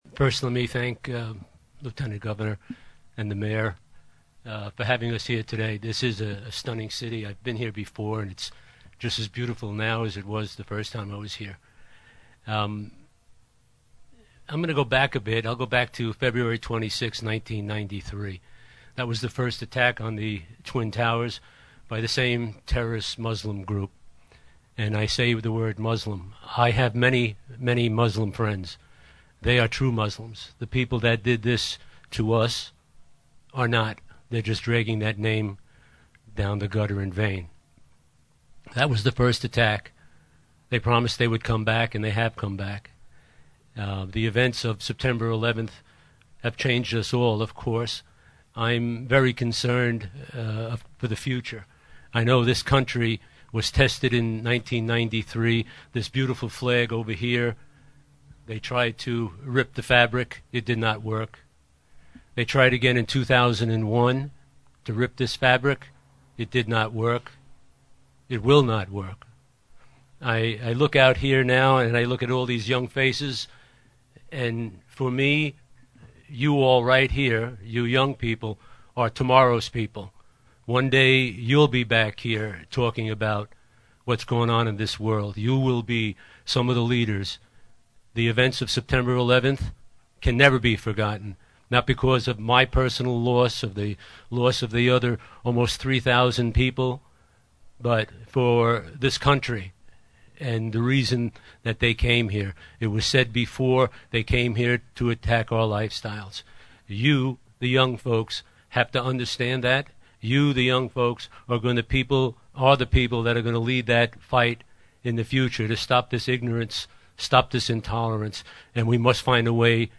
The ceremony was sometimes somber — but also filled with hope about the future.